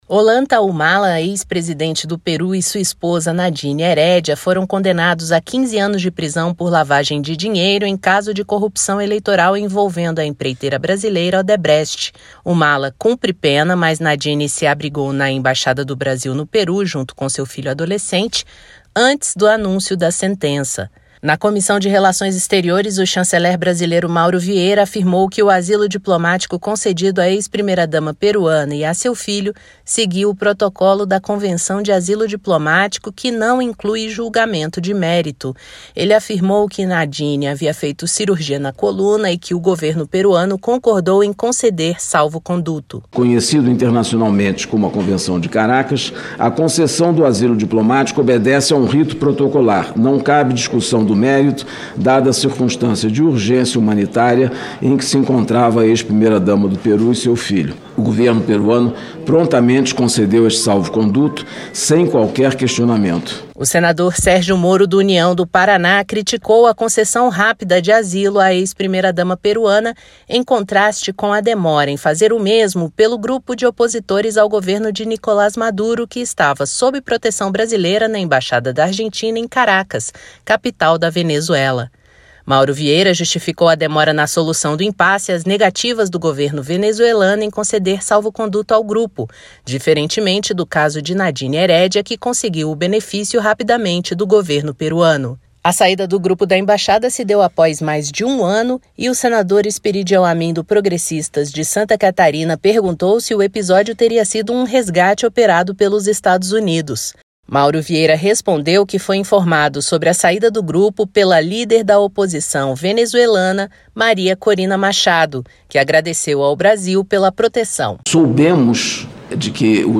O ministro das Relações Exteriores, Mauro Vieira, esteve na Comissão de Relações Exteriores nesta terça-feira (20) para falar sobre o asilo diplomático concedido à ex-primeira-dama peruana, Nadine Heredia, condenada pela justiça de seu país pelo crime de lavagem de dinheiro; e sobre o caso dos opositores ao governo de Nicolás Maduro, na Venezuela, que estavam sob a proteção do Brasil na embaixada da Argentina em Caracas.